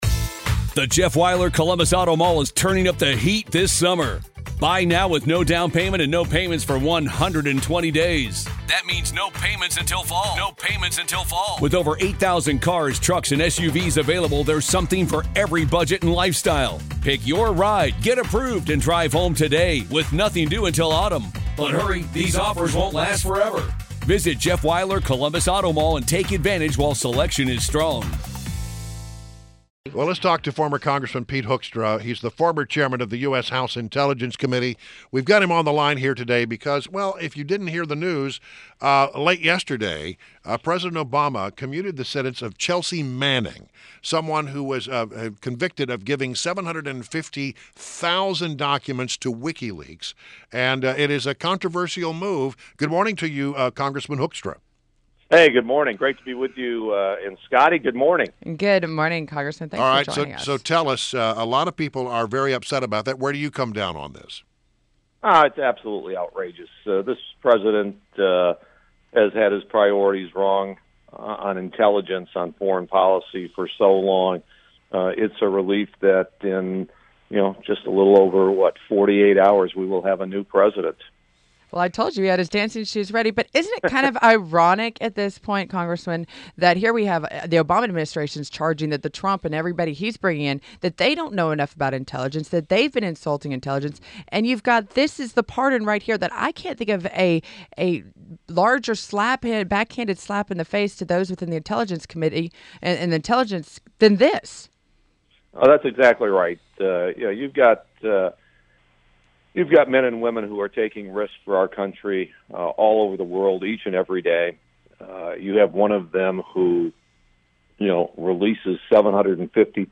INTERVIEW – Former Congressman PETE HOEKSTRA – (hook stra) – is the former Chairman of the U.S. House Intelligence Committee